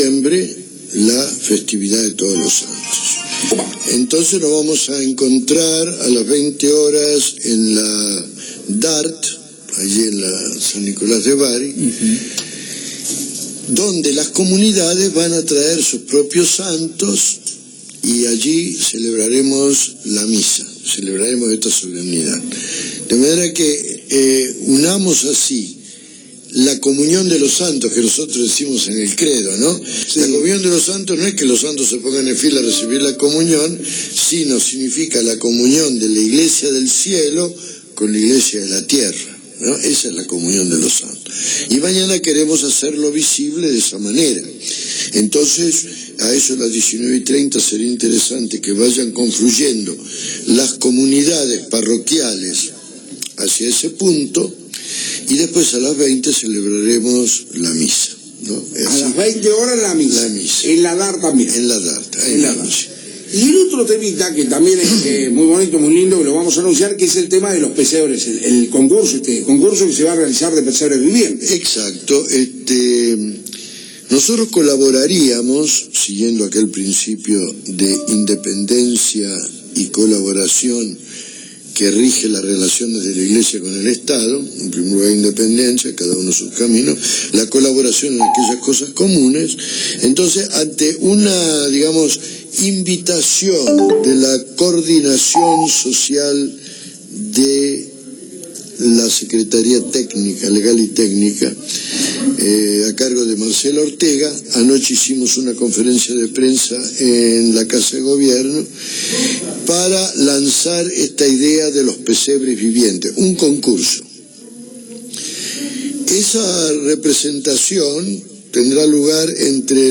roberto-rodrc3adguez-obispo-de-la-rioja-por-radio-fc3a9nix.mp3